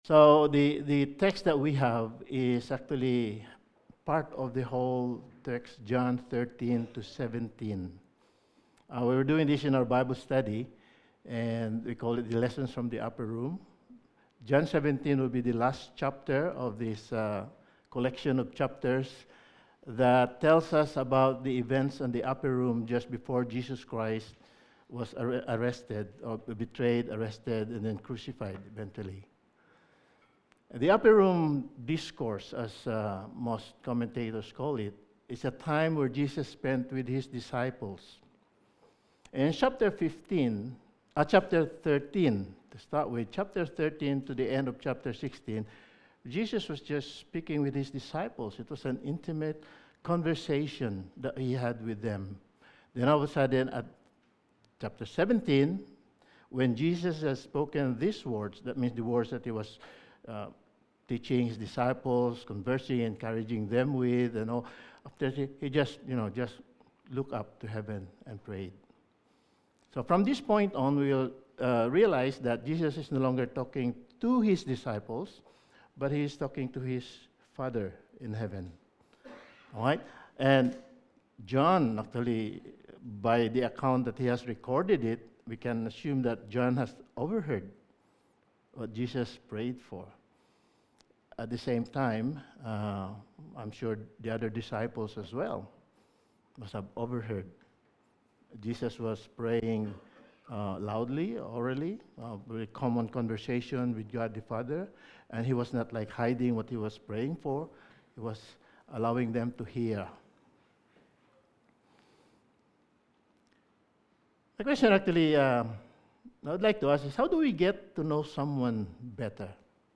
Topical Sermon